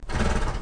c_horsexxx_atk1.wav